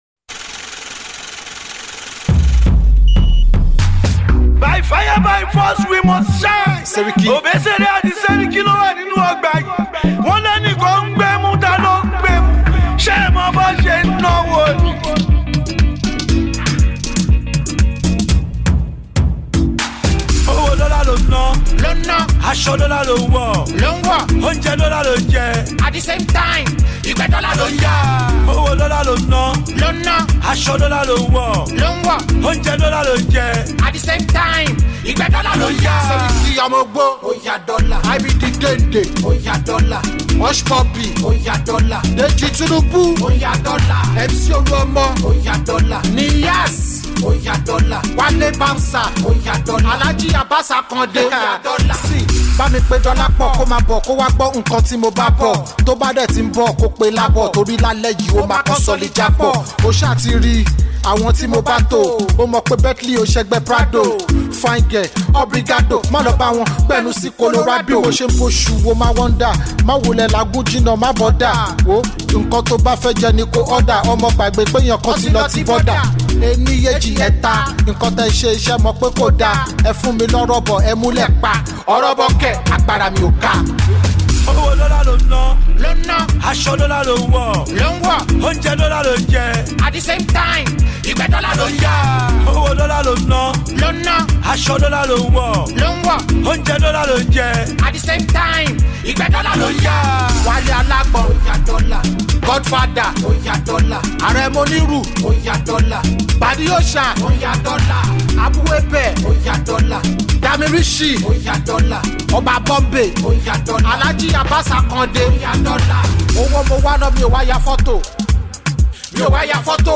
Indigenous rapper
veteran Fuji musician